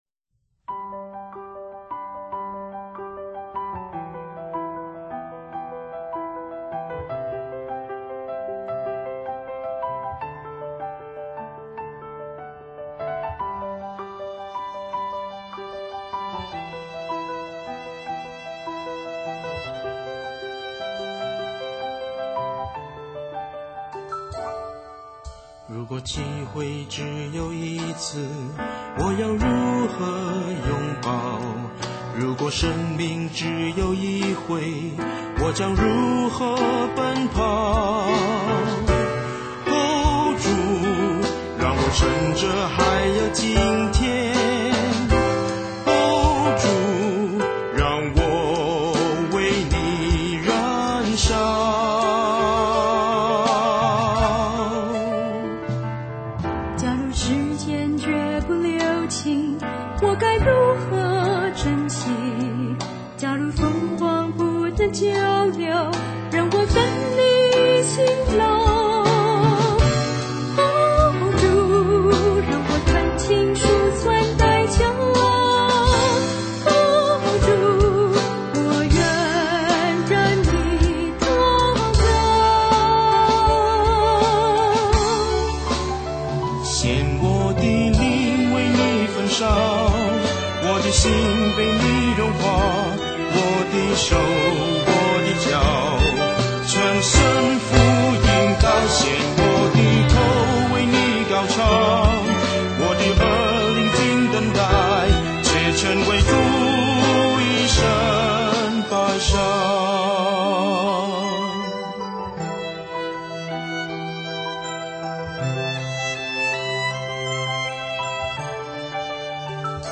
颂赞：《竭诚献上》